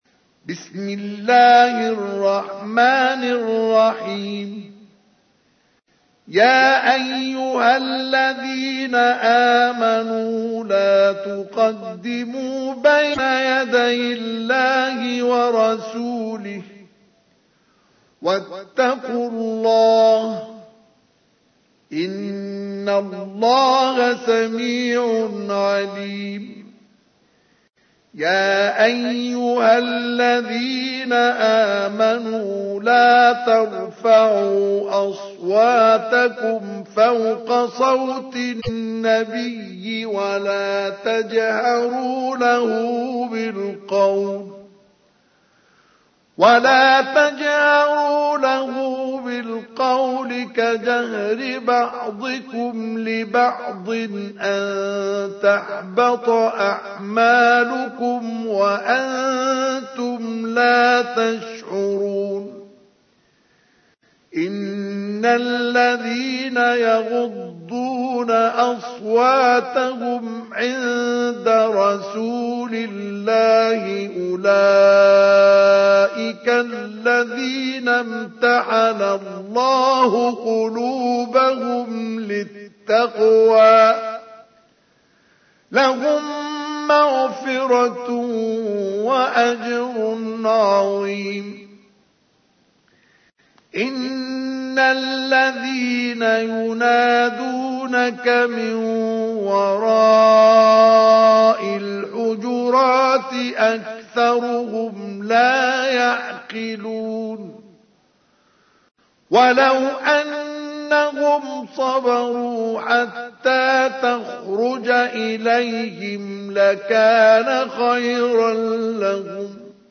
تحميل : 49. سورة الحجرات / القارئ مصطفى اسماعيل / القرآن الكريم / موقع يا حسين